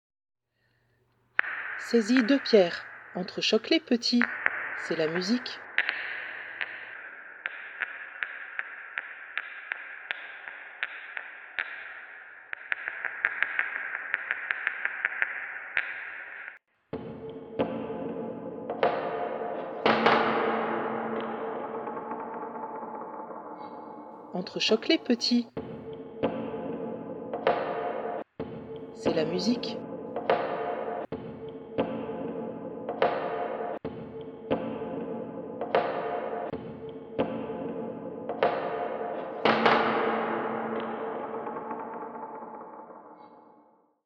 Je vous propose d’imaginer des Haïku(s) et de les arranger soit musicalement , soit à l’aide de sons glanés ci et là grâce à votre smartphone.
HAIKU-SONORE.mp3